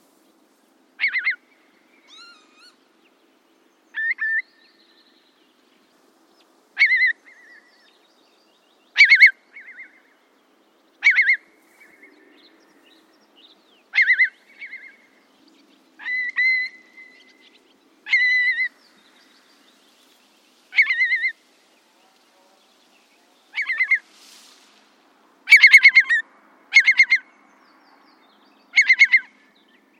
Courlis cendré - Mes zoazos
courlis-cendre.mp3